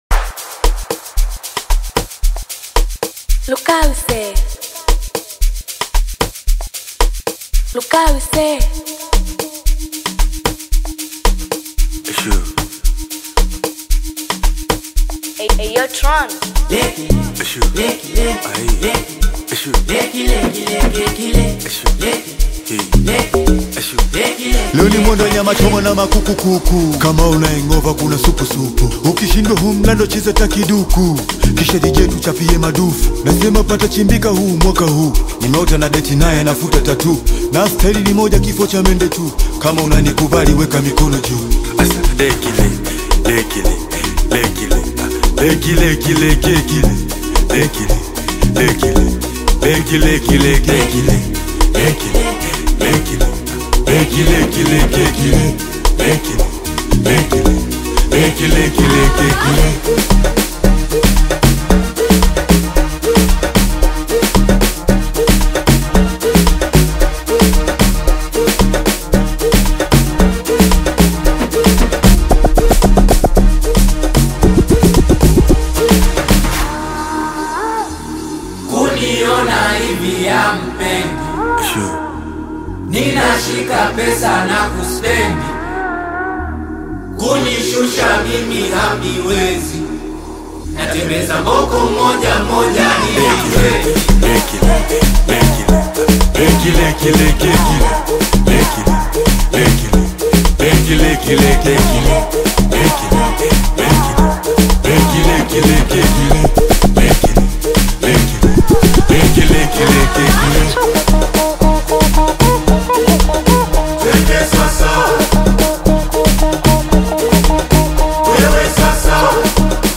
Tanzanian Bongo Flava
Bongopiano song
will sure make you dance and sing